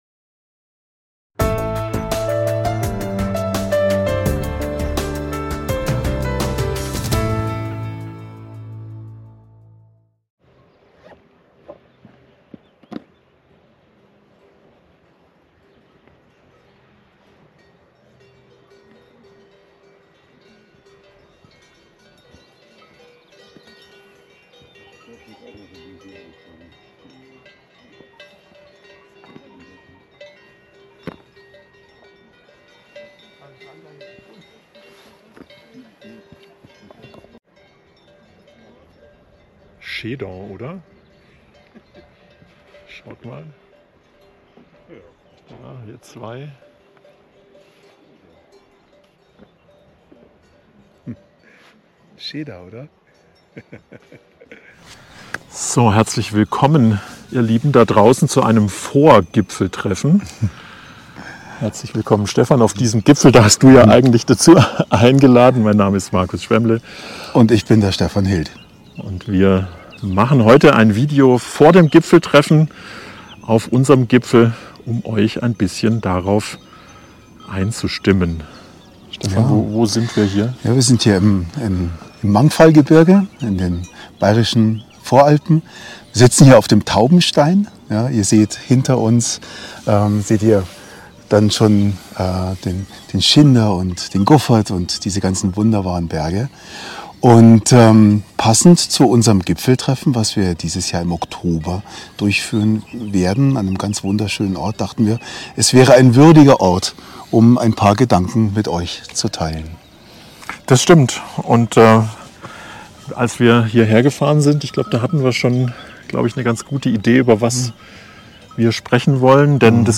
Entstanden ist ein lebendiges Gespräch über das, was unser Gipfeltreffen der Persönlichkeitsentwicklung so besonders macht.